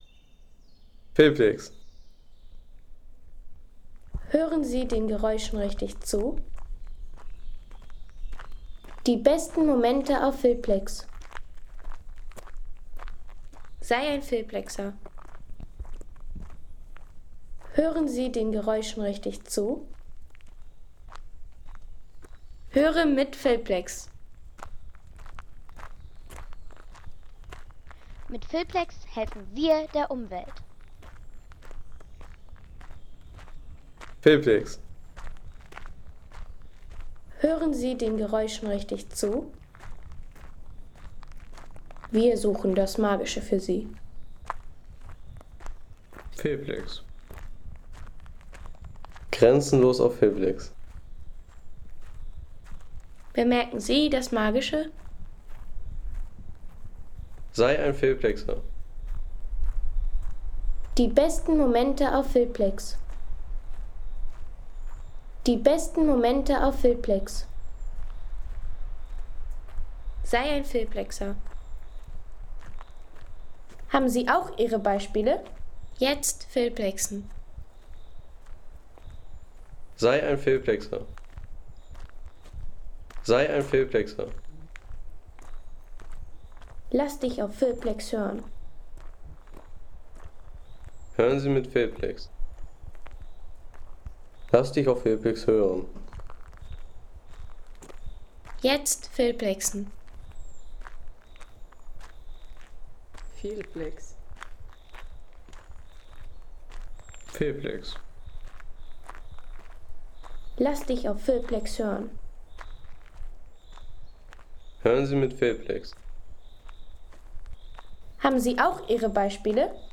Wanderung durch den Harz
Wanderung durch den Harz Home Sounds Menschen Wandern Wanderung durch den Harz Seien Sie der Erste, der dieses Produkt bewertet Artikelnummer: 60 Kategorien: Menschen - Wandern Wanderung durch den Harz Lade Sound.... Wanderung durch den Harz zum Brocken – Natur und Bewegung vereint.